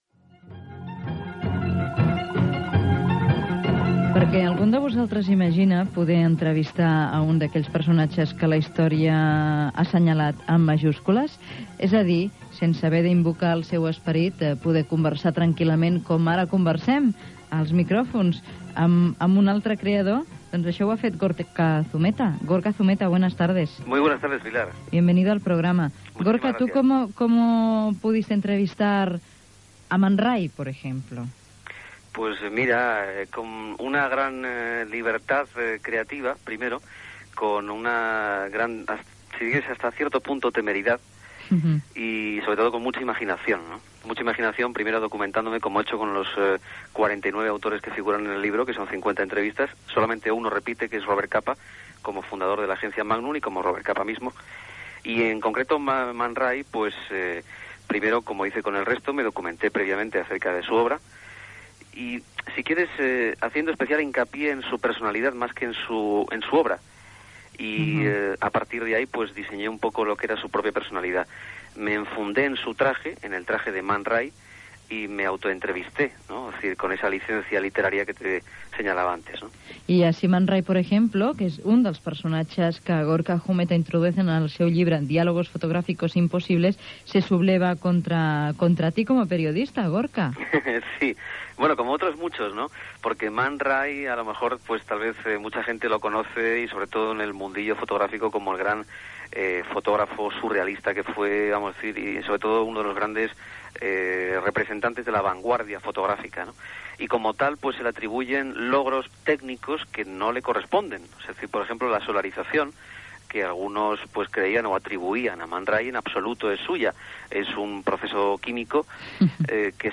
Entrevista
S'inclouen algunes dramatitzacions dialogades de fragments del llibre